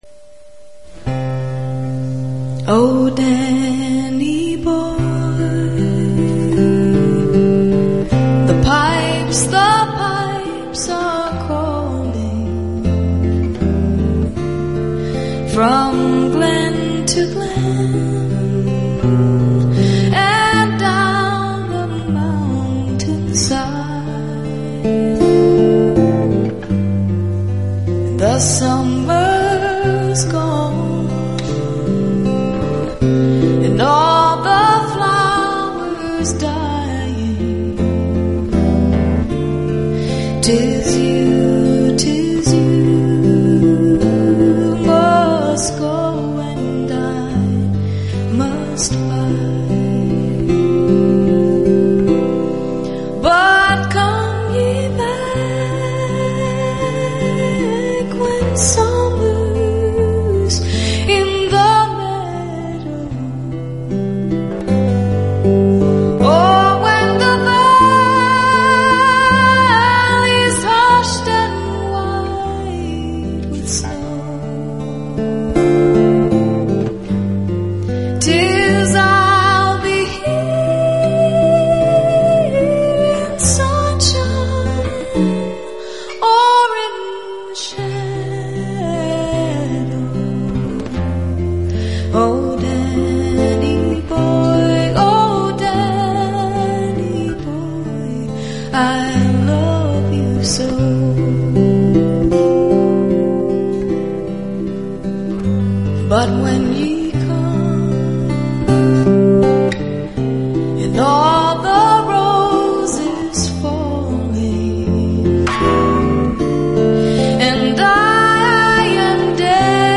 다른 특징을 살펴보면 이 곡도 대부분의 민요처럼 아일랜드 특유의 5음계를 구사한다.